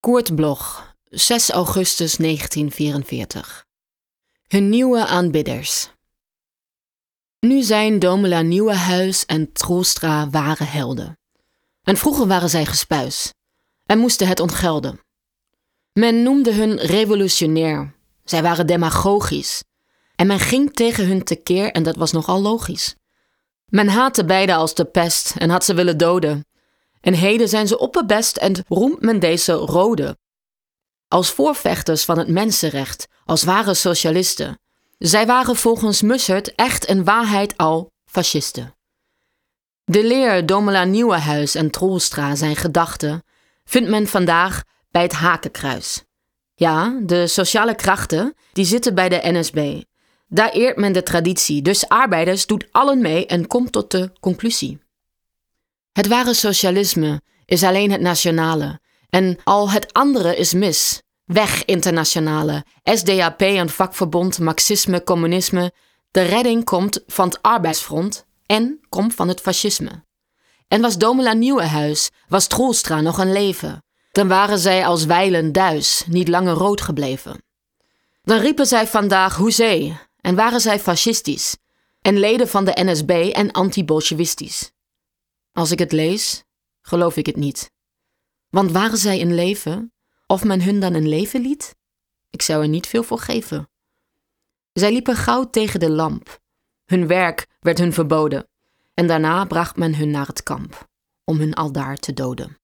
Aufnahme: Killer Wave Studios, Hamburg · Bearbeitung: Kristen & Schmidt, Wiesbaden